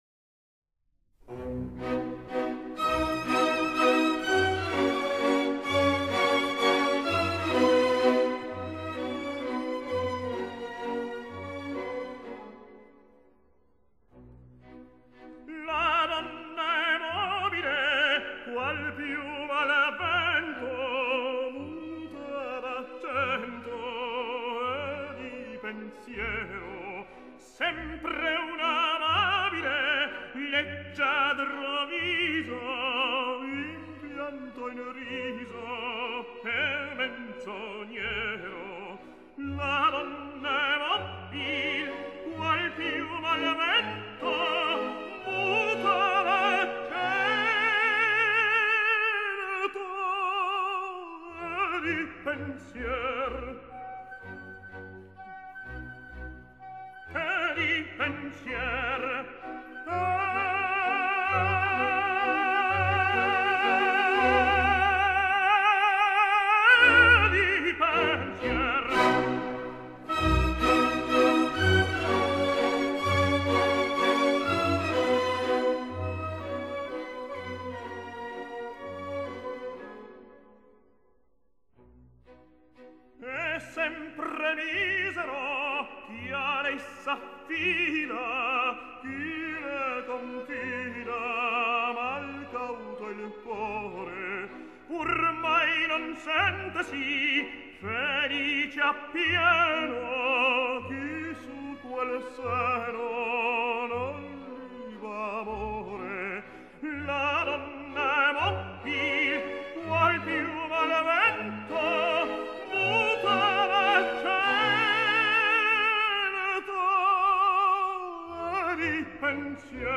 他的演唱嗓音丰满华丽，坚强有力，胜任从抒情到戏剧型的各类男高音角色。